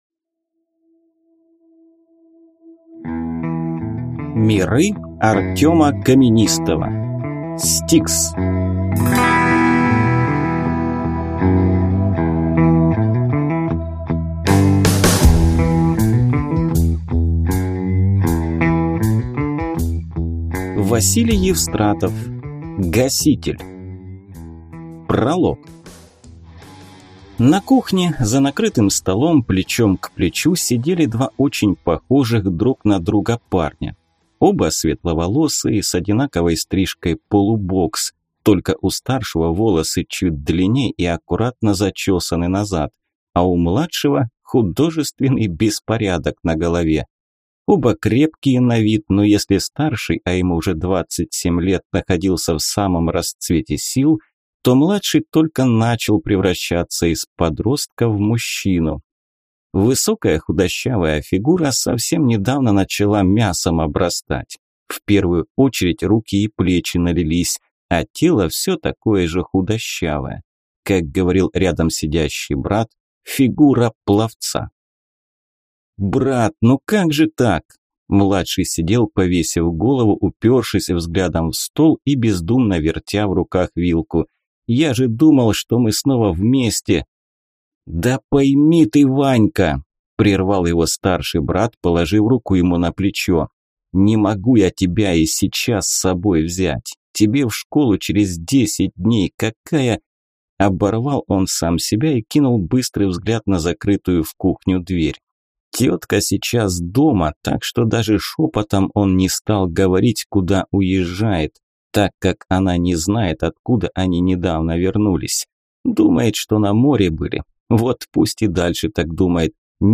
Аудиокнига S-T-I-K-S. Гаситель | Библиотека аудиокниг